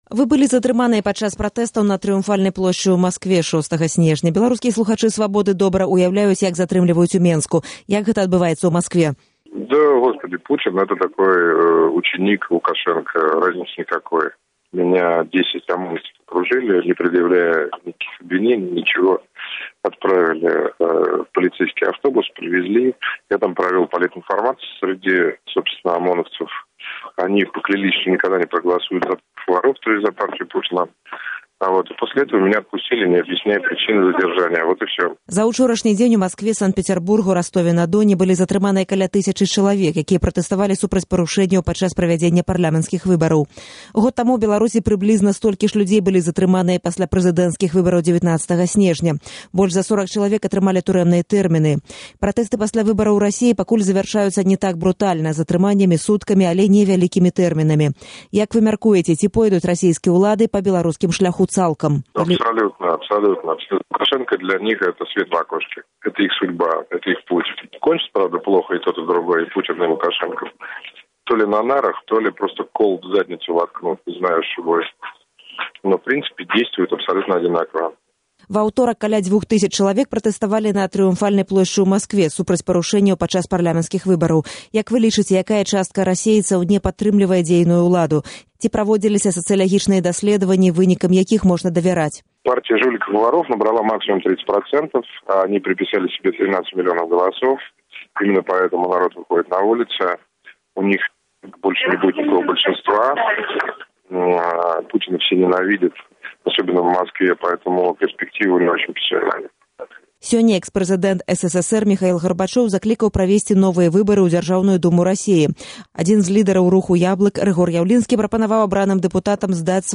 Інтэрвію з Барысам Нямцовым